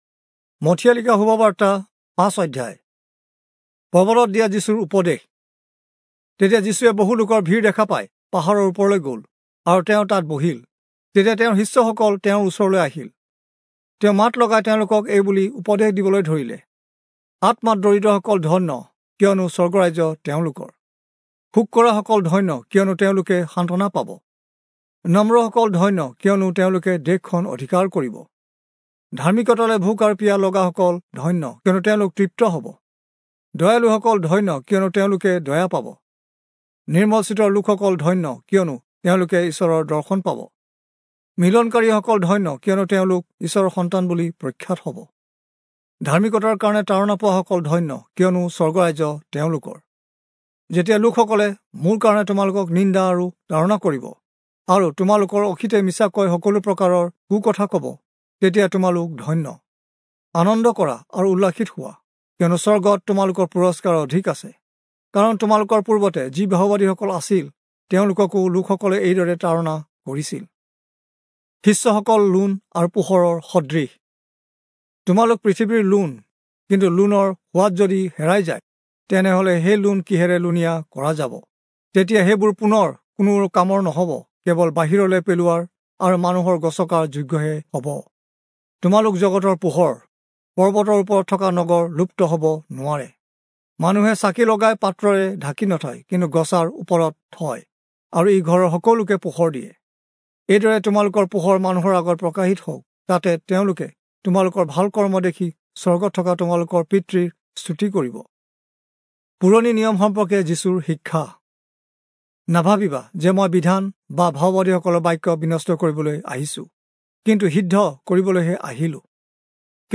Assamese Audio Bible - Matthew 5 in Alep bible version